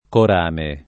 corame [ kor # me ] s. m.